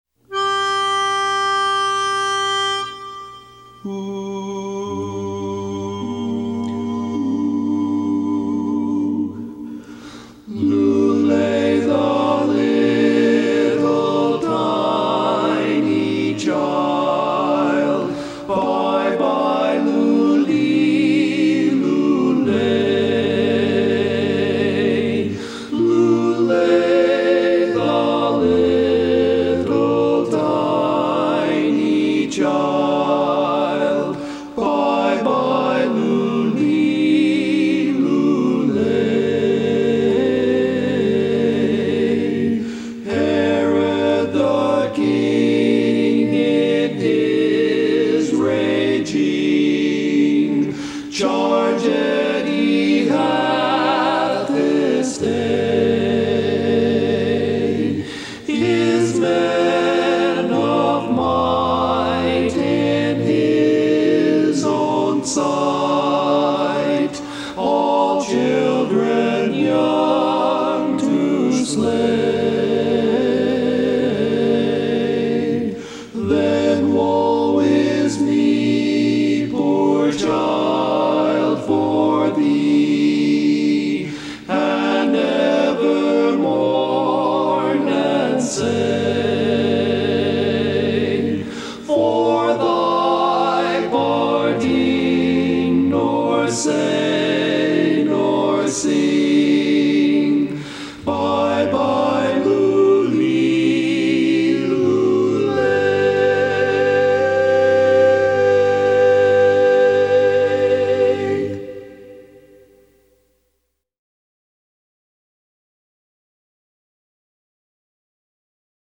Christmas Songs
Barbershop
Tenor